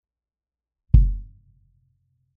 If you need more convincing here is a D6 placed outside a kick first On Axis and then aimed 90 degrees Off Axis; the gain was never adjusted.
The On Axis sound is much heavier and louder while the Off Axis is quieter and lost a lot of the boom; remember more gain to compensate will mean more hiss.